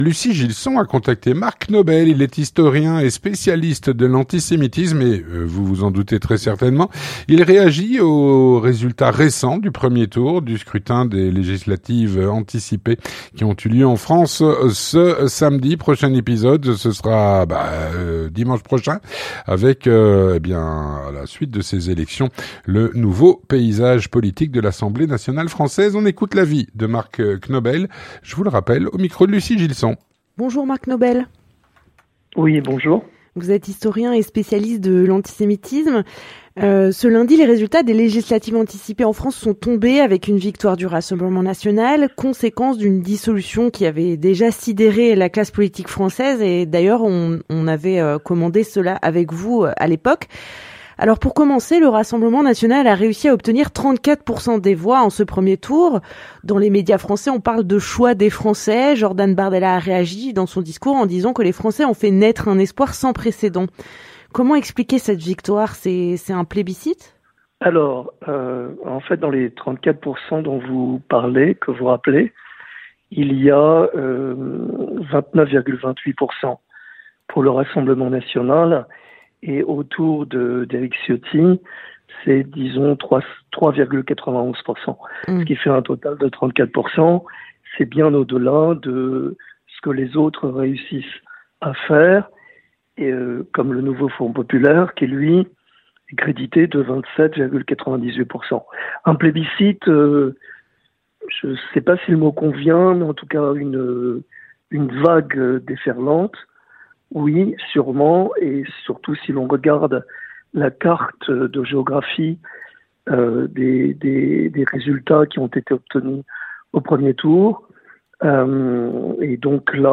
L'entretien du 18H - Réaction aux résultats du premier tour des législatives anticipées en France.